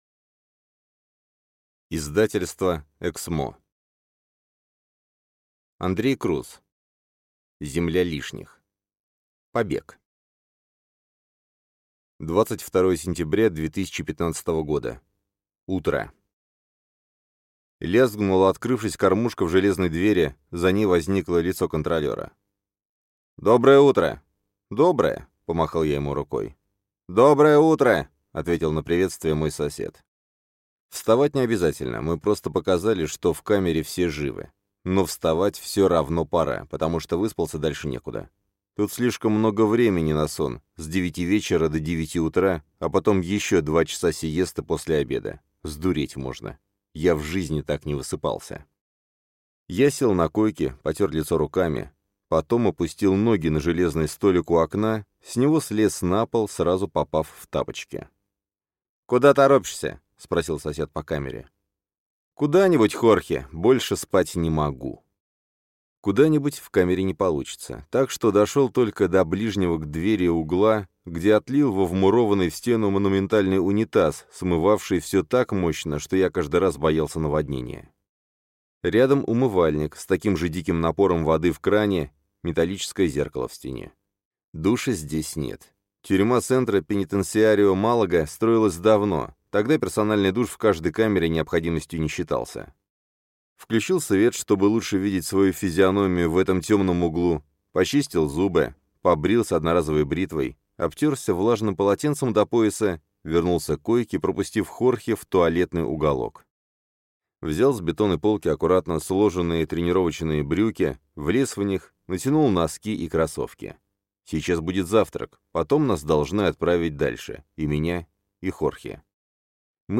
Аудиокнига Земля лишних. Побег | Библиотека аудиокниг